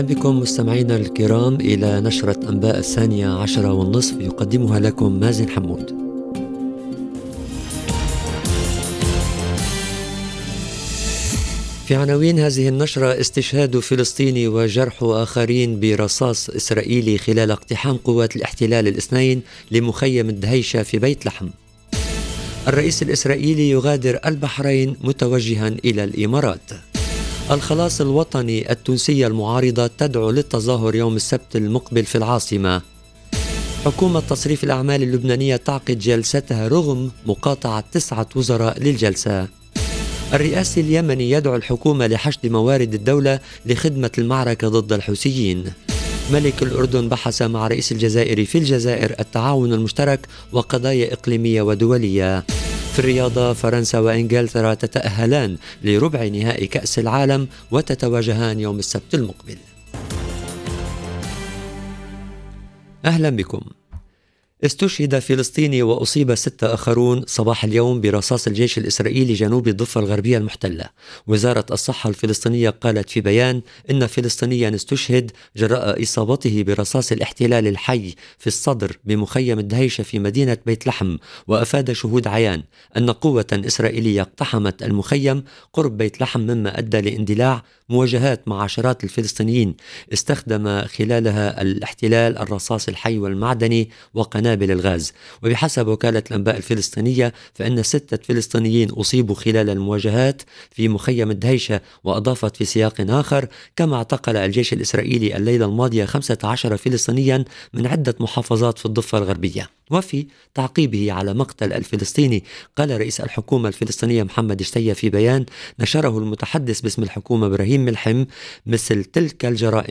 LE JOURNAL EN LANGUE ARABE DE MIDI 30 DU 5/12/22